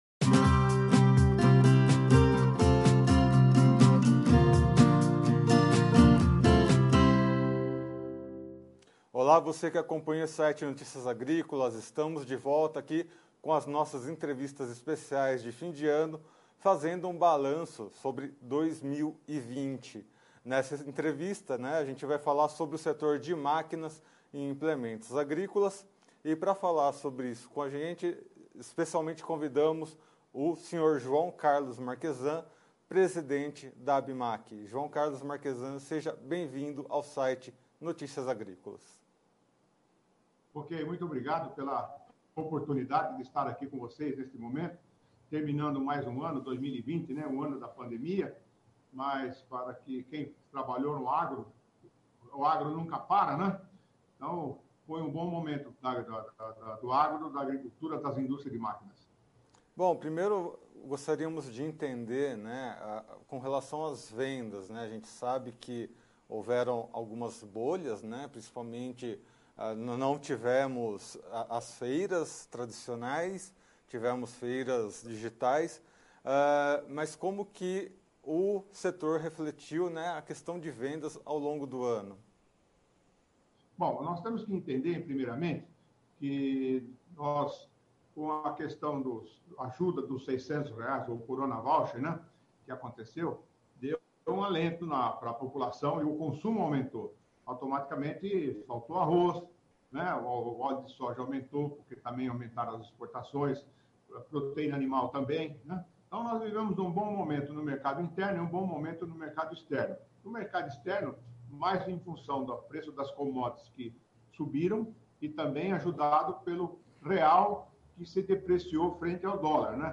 Veja mais na entrevista acima.